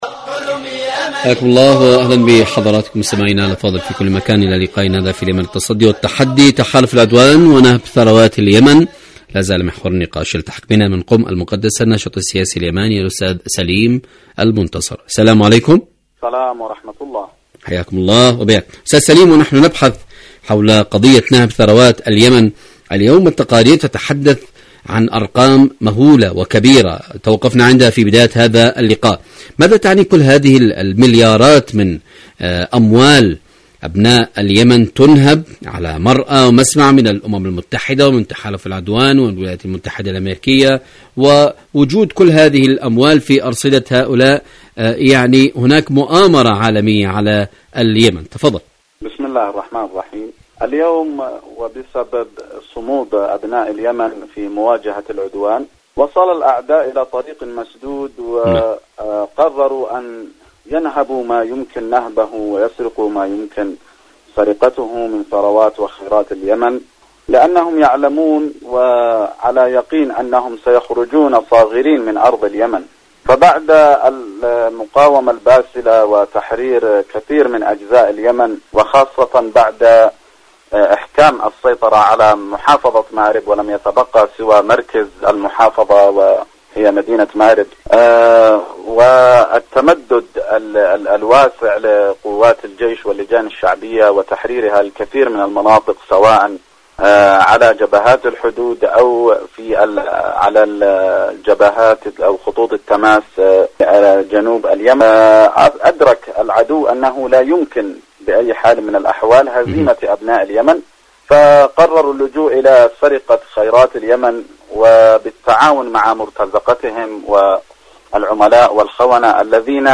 برنامج اليمن التصدي والتحدي مقابلات إذاعية